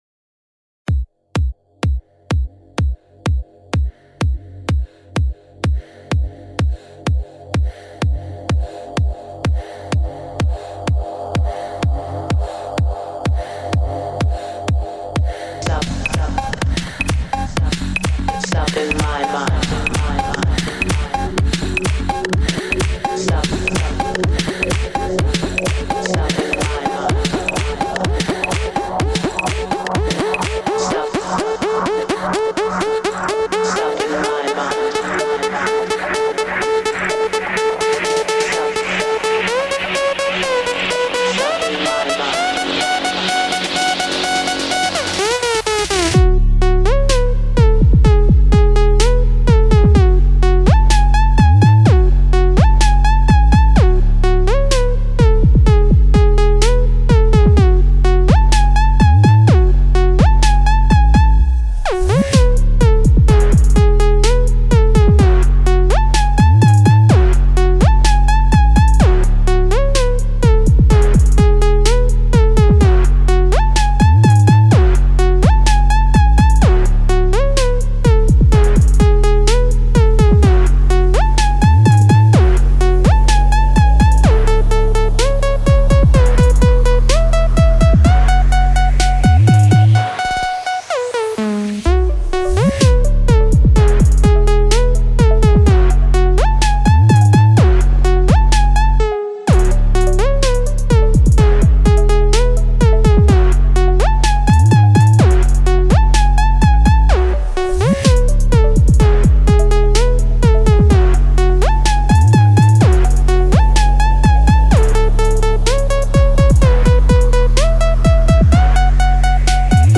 High quality Sri Lankan remix MP3 (6.1).
Rap